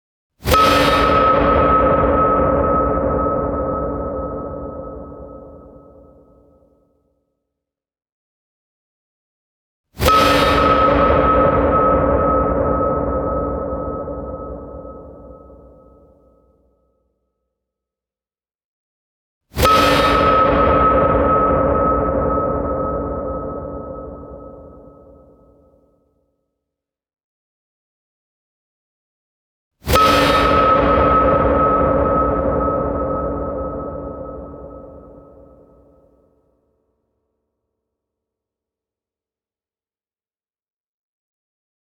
clang ding fright horror jump jumpscare ominous request sound effect free sound royalty free Sound Effects